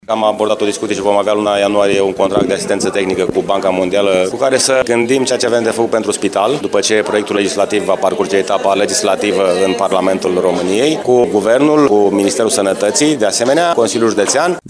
Primarul George Scripcaru arată că viitorul Spital al Brașovului ar putea beneficia de o finanțare din partea BERD, precum și de asistență tehnică din partea specialiștilor băncii: